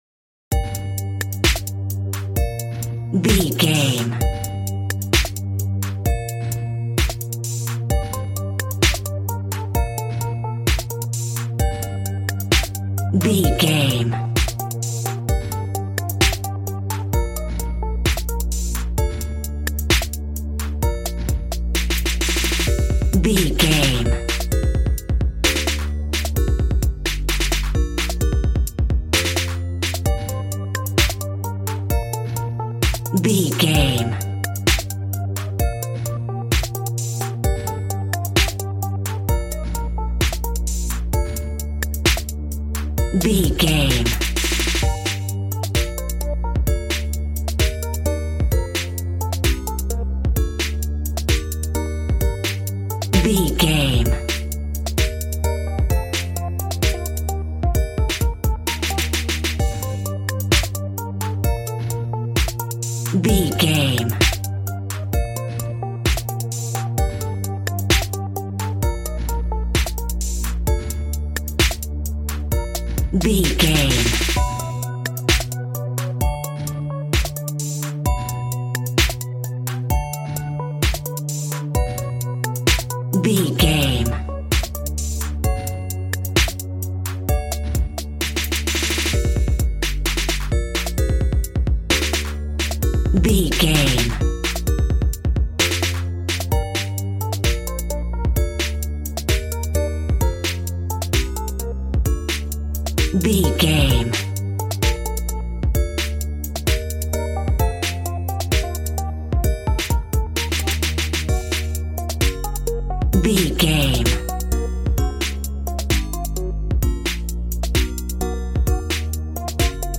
A groovy and explosive piece of cool street hip hop.
Aeolian/Minor
Fast
groovy
synthesiser
drums
piano